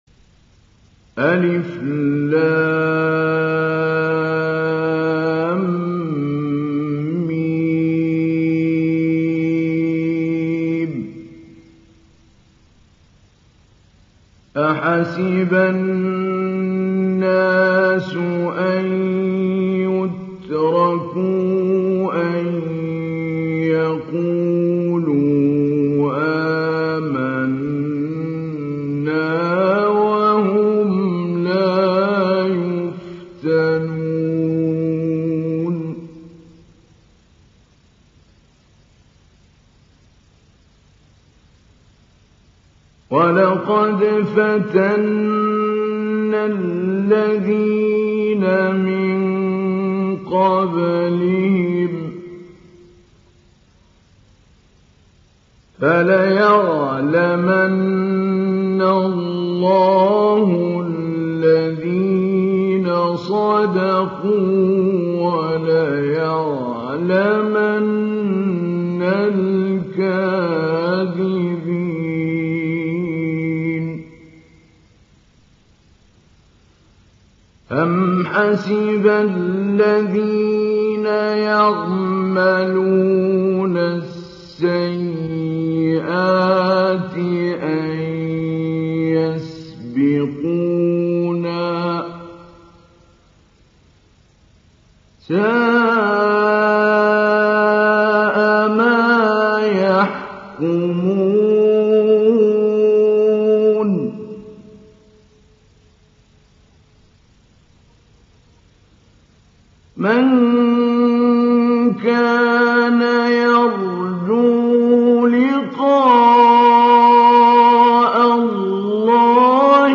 Download Surah Al Ankabut Mahmoud Ali Albanna Mujawwad